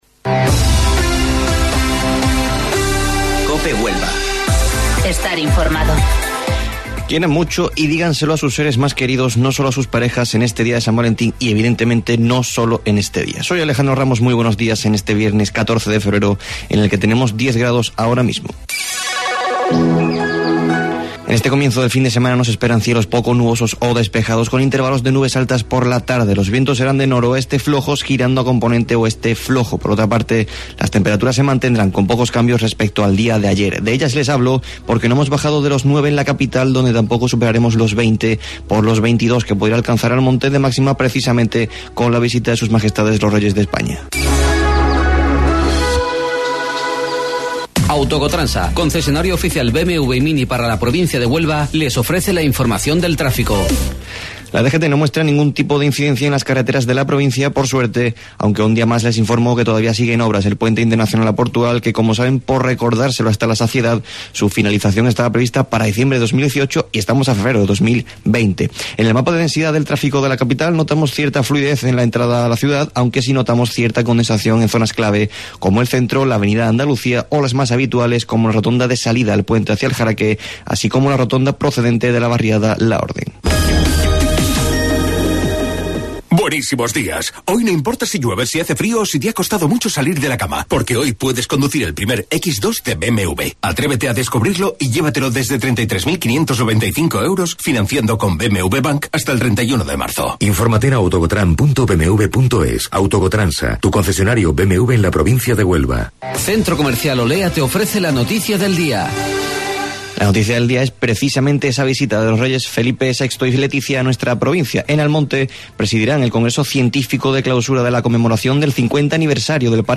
AUDIO: Informativo Local 08:25 del 14 Febrero